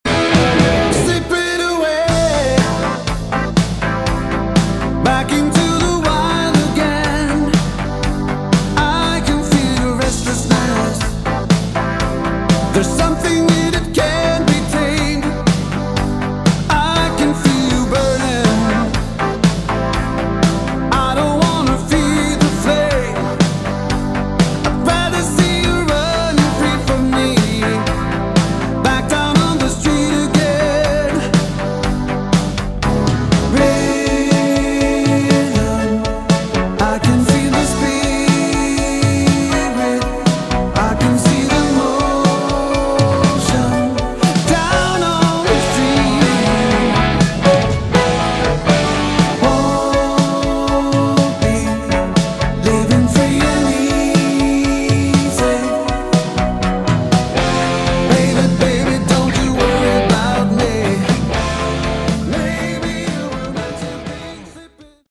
Category: AOR / Melodic Rock
lead, backing vocals
lead guitar
keyboards, piano
bass guitar